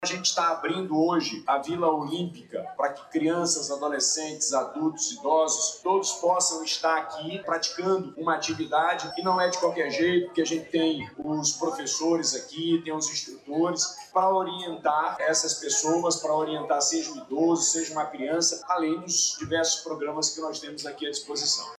Durante o lançamento do projeto, o governador Wilson Lima ressaltou que as práticas esportivas e de lazer serão gratuitas e voltadas a todas as faixas etárias.